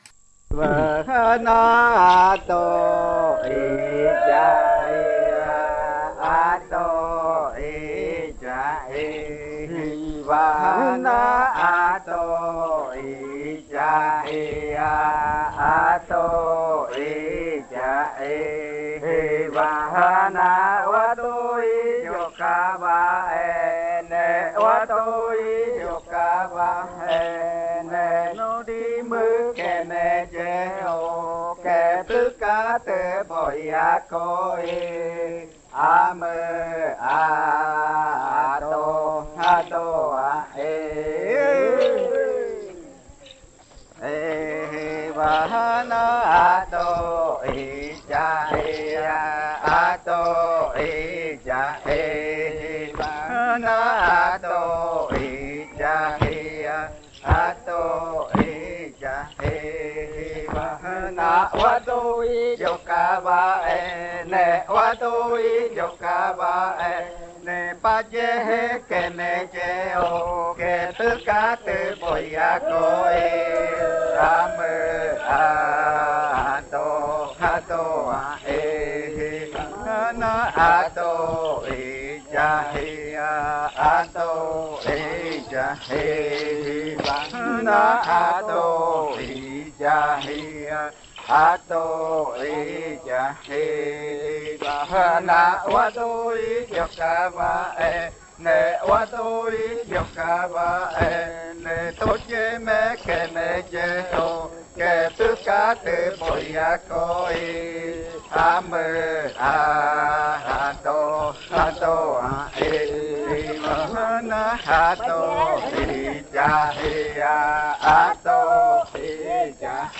47. Baile de nombramiento. Canto n°1
Puerto Remanso del Tigre, departamento de Amazonas, Colombia